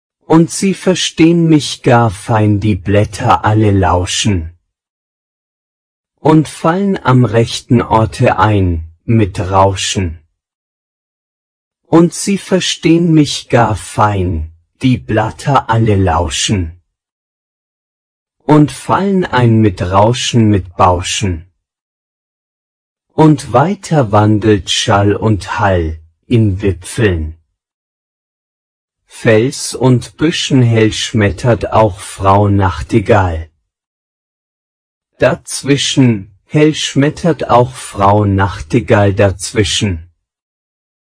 voix homme(22-51)
im-wald-prononce-garcon-22-51.mp3